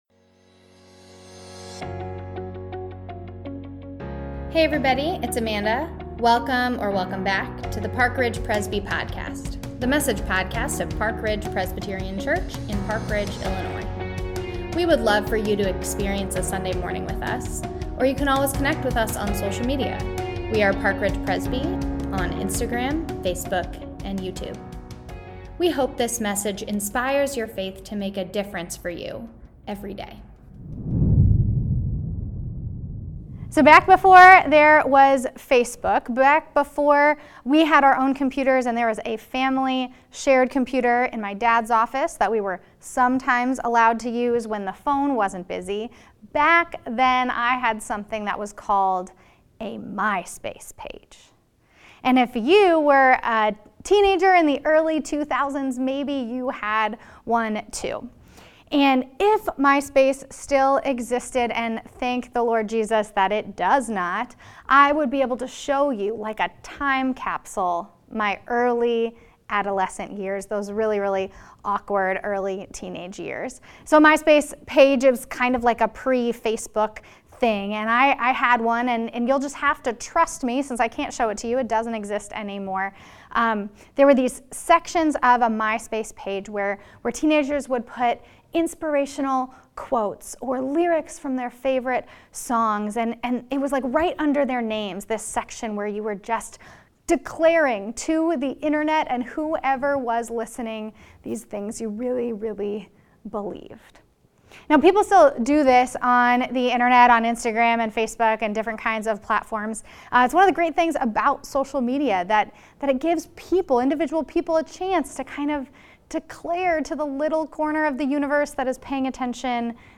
To Be Continued Week Two | Online Church | Sunday May 1, 2022
sermon